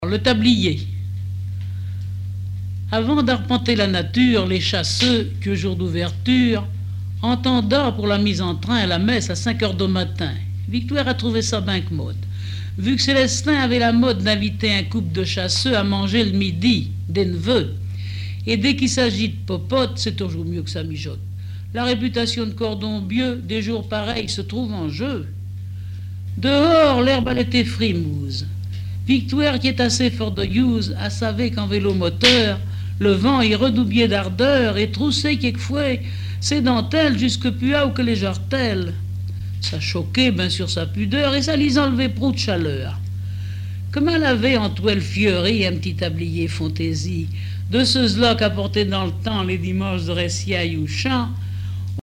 Genre récit
textes en patois et explications sur la prononciation